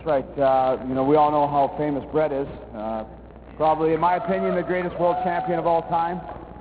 MAY 25, 1998 Atlantic Canada Morning Show--BREAKFAST TELEVISION
Owen Hart gets interviewed---a good one too---he was more than personable for this shoot.